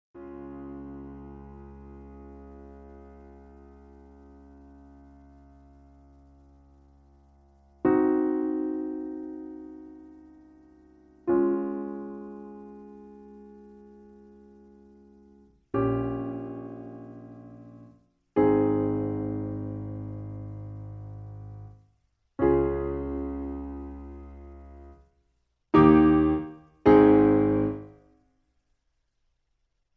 In D flat. Piano Accompaniment